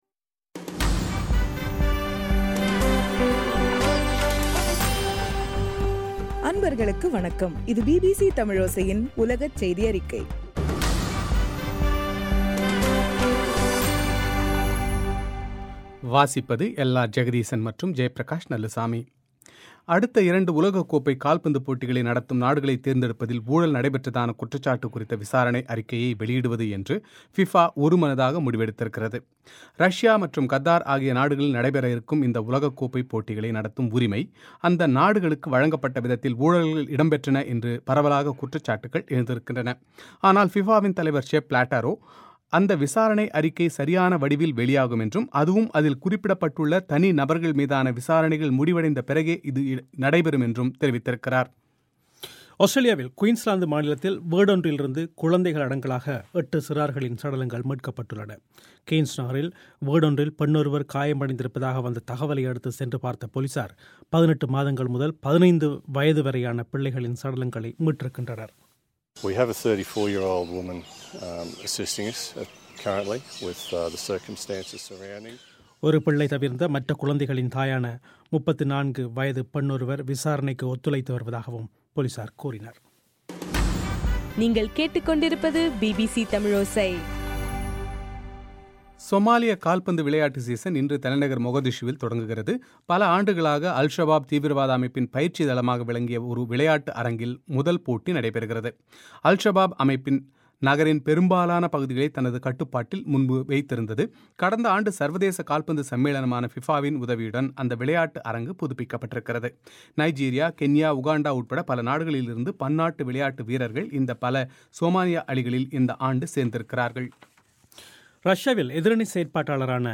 பிபிச தமிழோசை, செய்தியறிக்கை டிசம்பர் 19